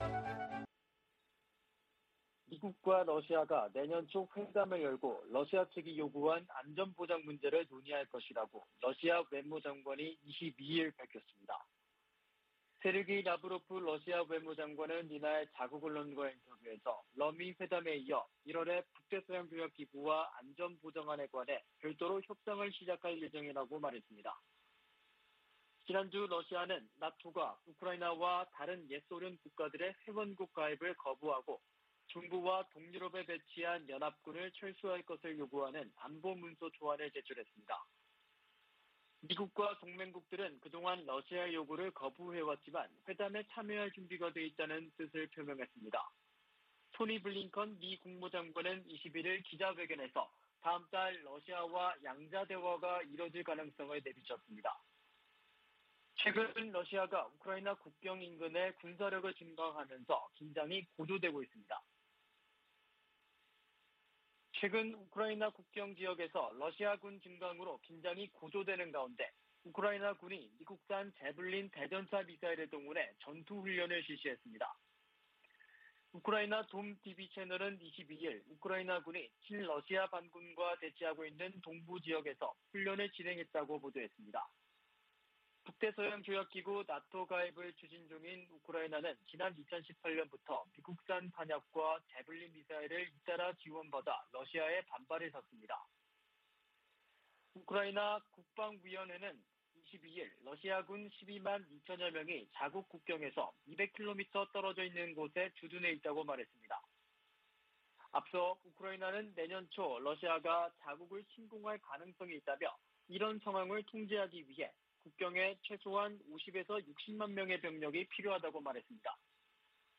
VOA 한국어 아침 뉴스 프로그램 '워싱턴 뉴스 광장' 2021년 12월 23일 방송입니다. 조 바이든 미국 대통령은 2022년 회계연도에도 북한과 쿠바 등에 인도적 목적 이외 비무역 관련 지원을 하지 않을 것이라고 밝혔습니다. 토니 블링컨 미 국무장관은 인도태평양 지역에 정책과 자원을 집중하고 있다고 밝혔습니다. 미국과 한국은 한국의 증대된 역량과 자율성에 대한 열망을 감안해 동맹을 조정하고 있다고 미 의회조사국이 분석했습니다.